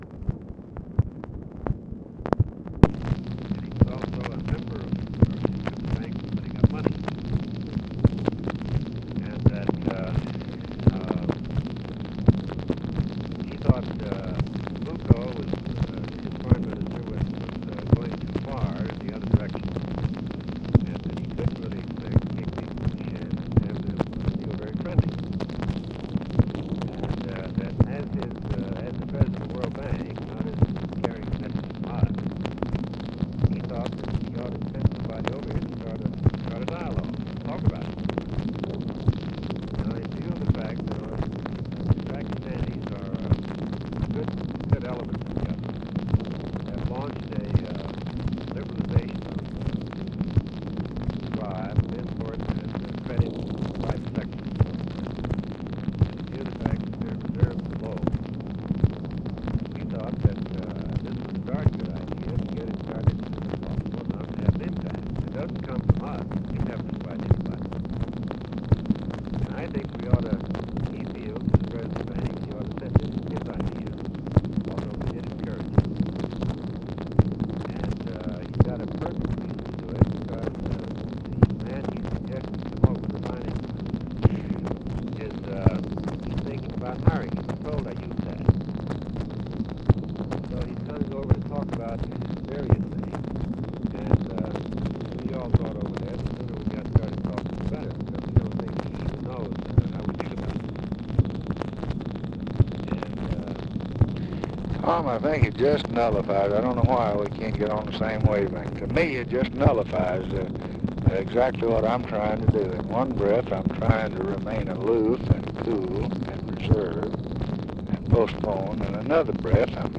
Conversation with THOMAS MANN, June 30, 1965
Secret White House Tapes